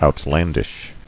[out·land·ish]